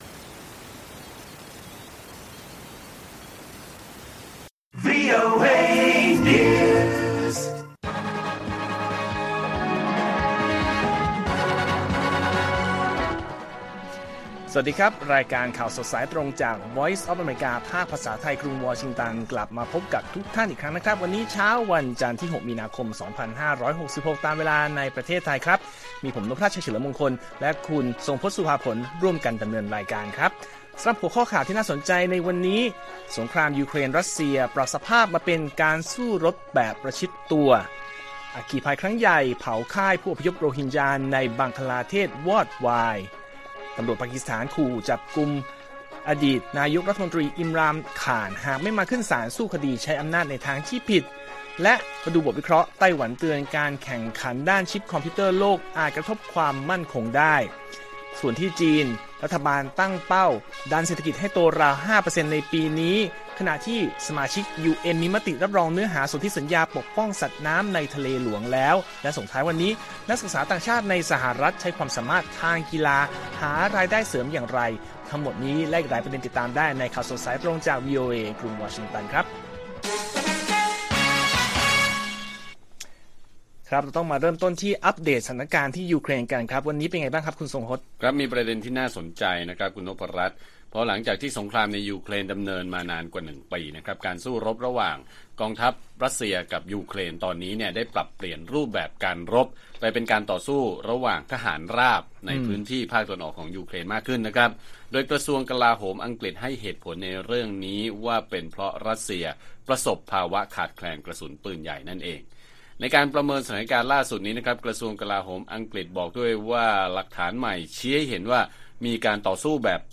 ข่าวสดสายตรงจากวีโอเอไทย 6:30 – 7:00 น. วันที่ 6 มี.ค. 2566